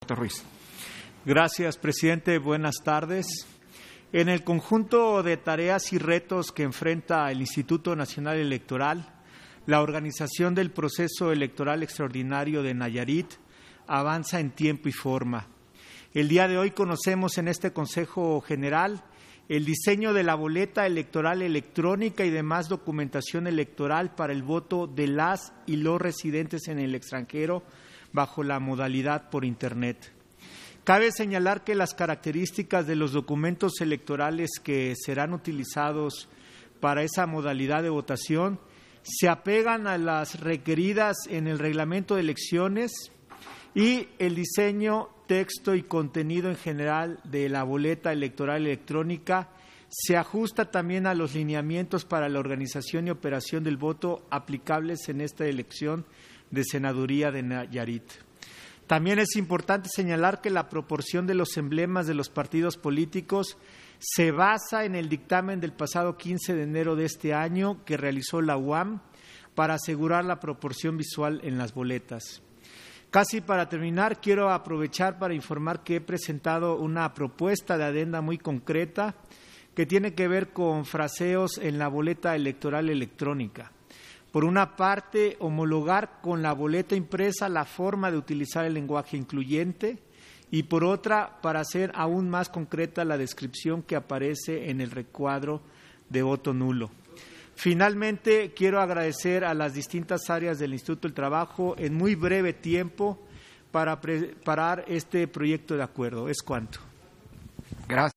Intervención de José Roberto Ruiz, en Sesión Ordinaria, por el que aprueba la documentación electoral para el voto de las y los mexicanos residentes en el extranjero, para la elección extraordinaria de una senaduria en Nayarit